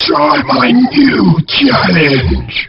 Line of Bubbler in Diddy Kong Racing.